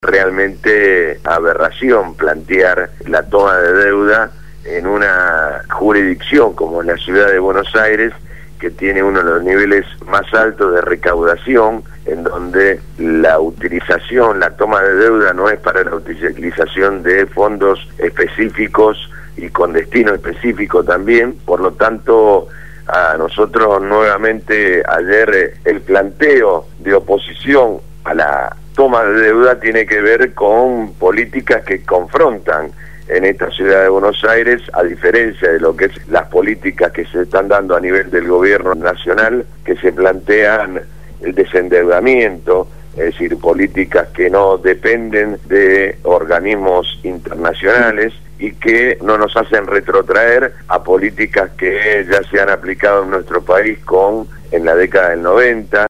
Francisco «Tito» Nenna, Legislador Porteño por el Frente Para la Victoria, habló en el programa Punto de Partida (Lunes a viernes de 7 a 9 de la mañana) de Radio Gráfica FM 89.3 sobre la decisión de la Legislatura de endeudar -a pedido del Jefe de Gobierno- a la Ciudad por 500 millones de dólares.